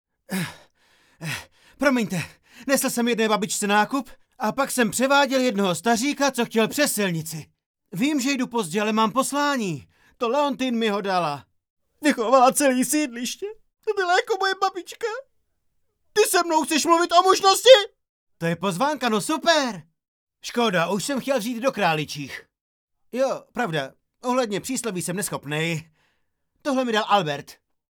Dabing: